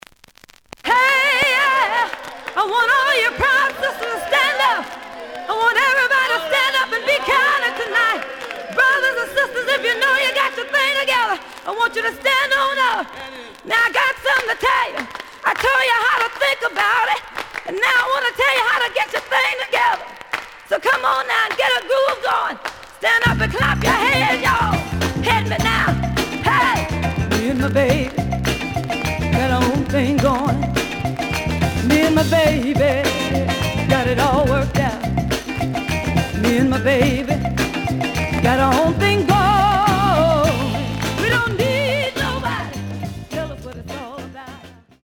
The audio sample is recorded from the actual item.
●Format: 7 inch
●Genre: Funk, 70's Funk